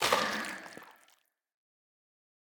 Minecraft Version Minecraft Version 1.21.5 Latest Release | Latest Snapshot 1.21.5 / assets / minecraft / sounds / block / sculk_shrieker / break6.ogg Compare With Compare With Latest Release | Latest Snapshot
break6.ogg